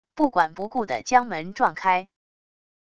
不管不顾的将门撞开wav音频